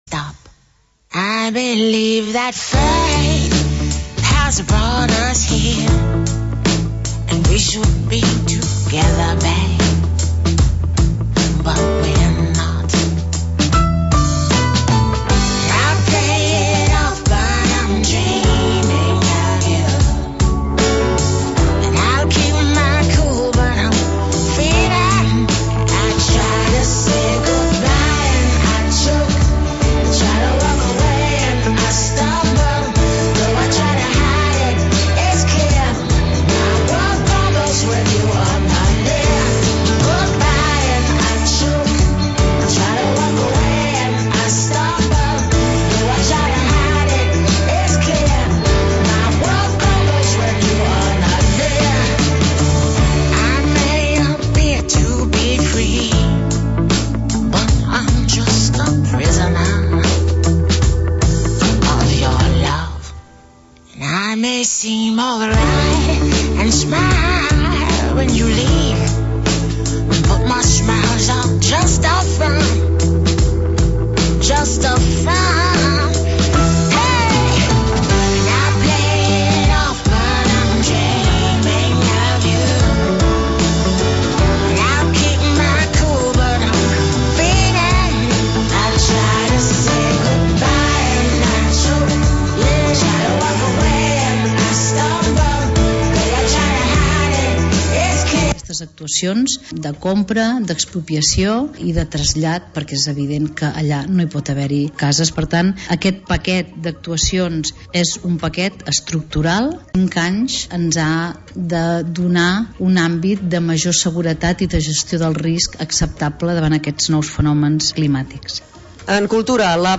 Informatiu territorial